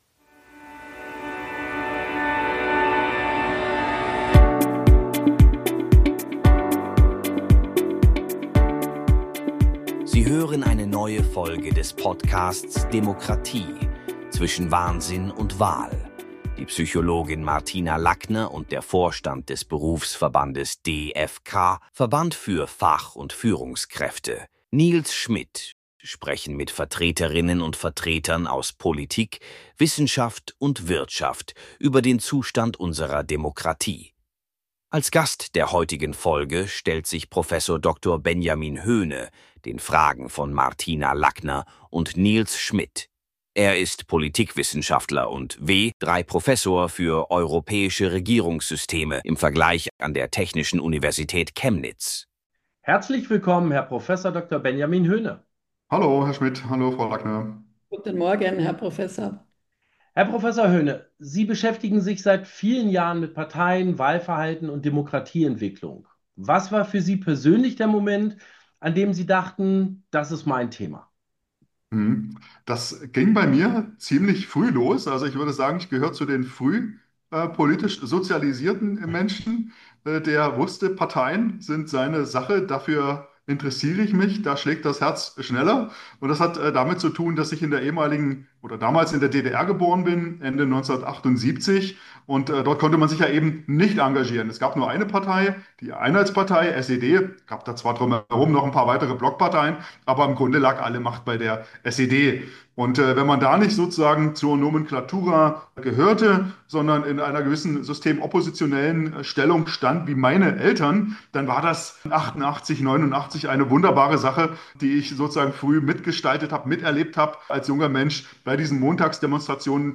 sprechen mit Vertreterinnen und Vertretern aus Politik, Wissenschaft und Wirtschaft über den Zustand unserer Demokratie.